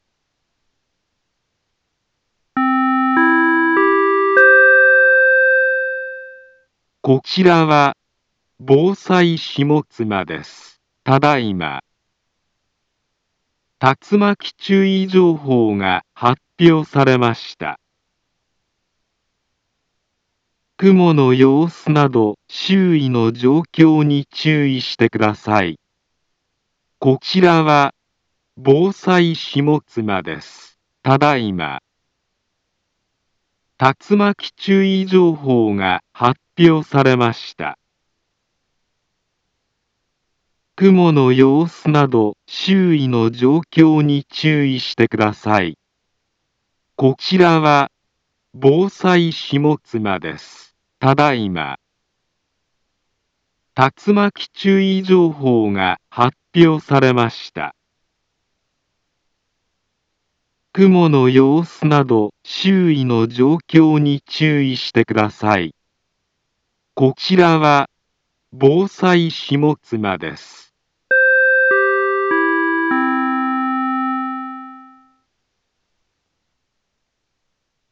Back Home Ｊアラート情報 音声放送 再生 災害情報 カテゴリ：J-ALERT 登録日時：2023-09-05 20:59:58 インフォメーション：茨城県南部は、竜巻などの激しい突風が発生しやすい気象状況になっています。